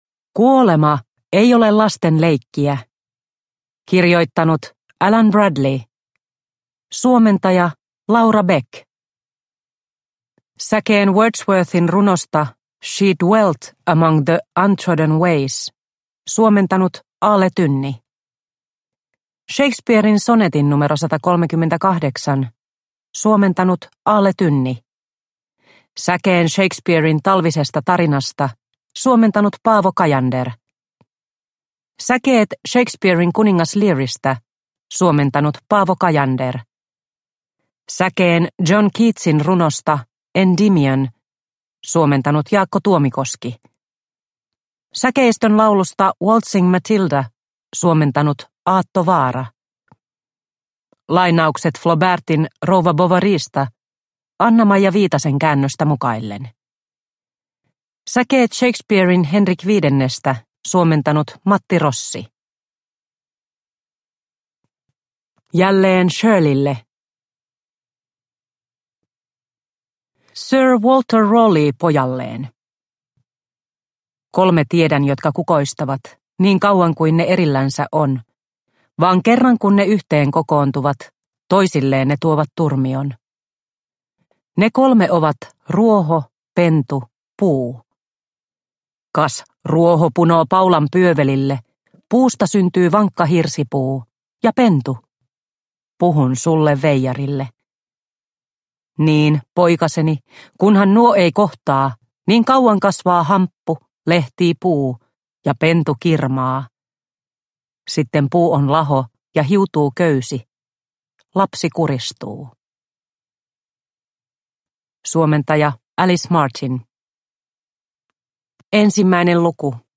Kuolema ei ole lasten leikkiä – Ljudbok – Laddas ner